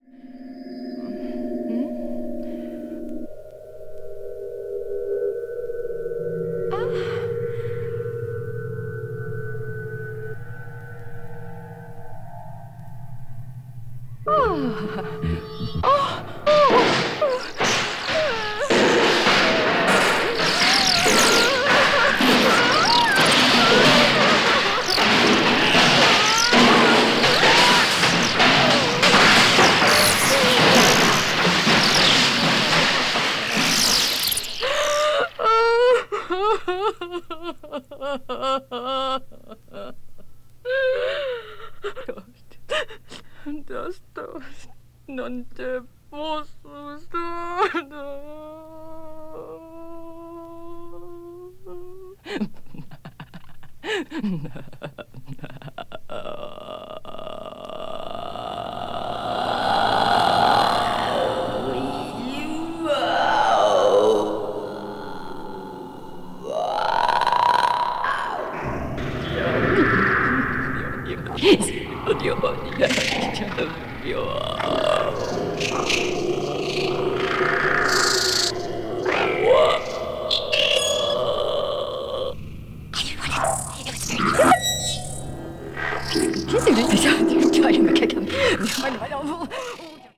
media : EX+/EX(わずかにチリノイズが入る箇所あり)